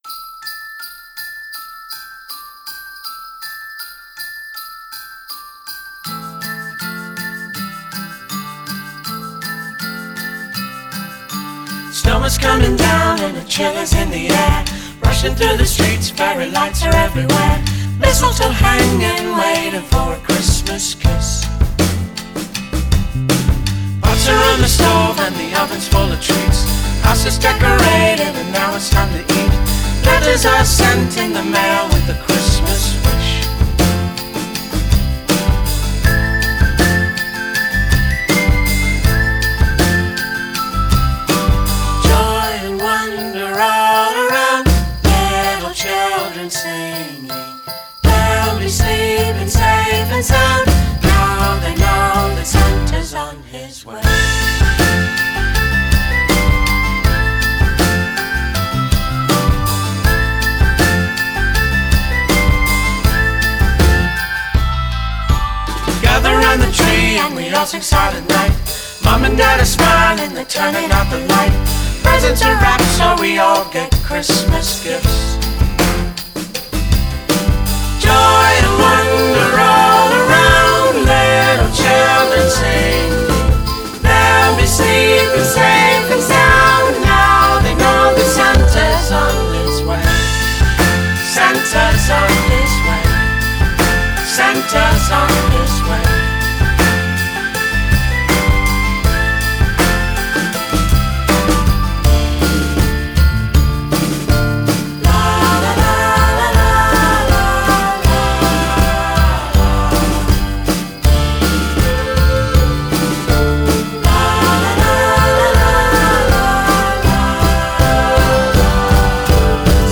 Original & traditional Christmas songs by indie artists, plus fave memories they share